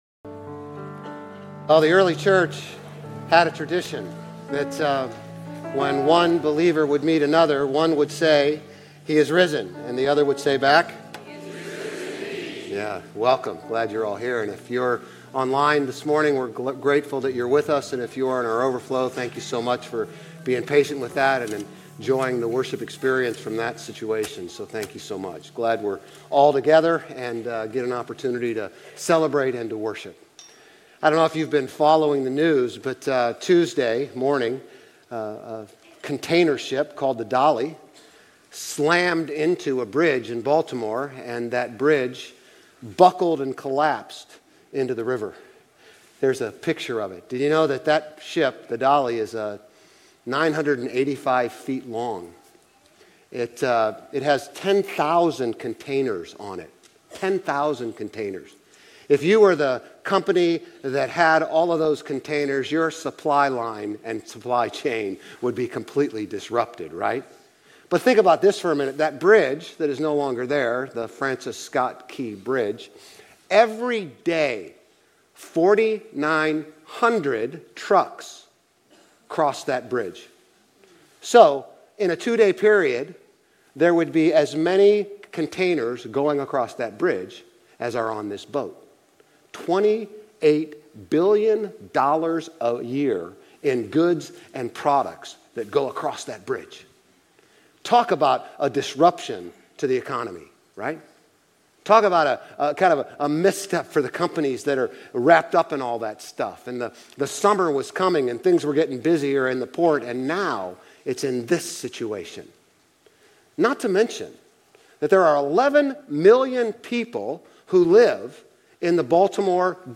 Grace Community Church Old Jacksonville Campus Sermons Easter Sunday Apr 01 2024 | 00:37:03 1x 00:00 / 00:37:03 Subscribe Share RSS Feed Share Link Embed